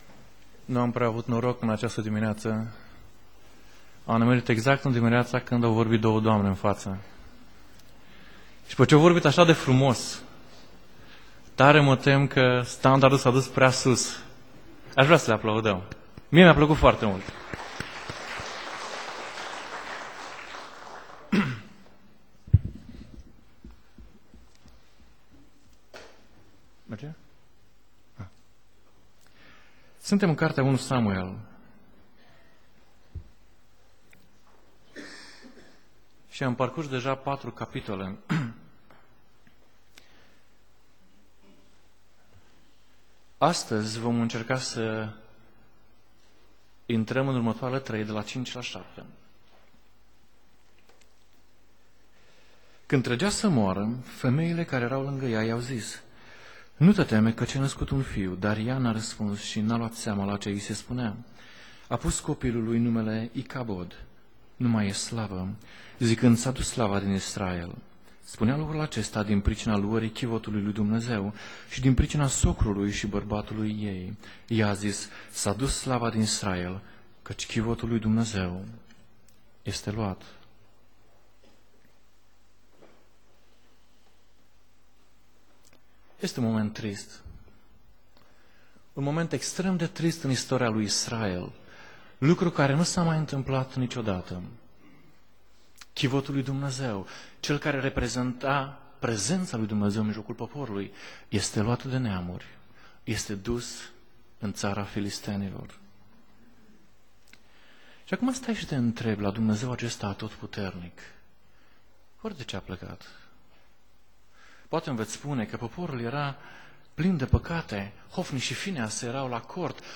Tip Predica Exegeza